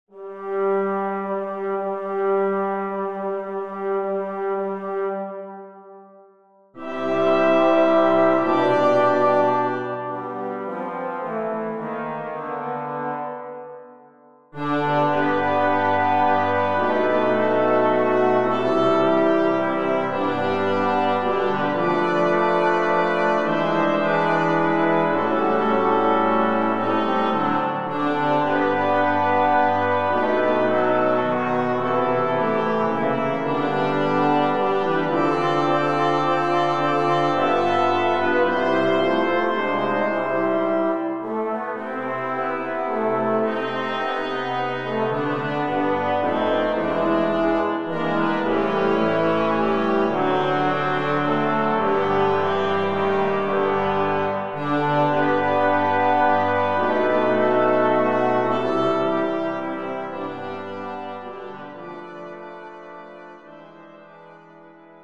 Mixed choir.